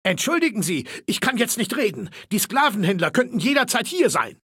Datei:Maleold01 ms06 greeting 0005d59f.ogg
Fallout 3: Audiodialoge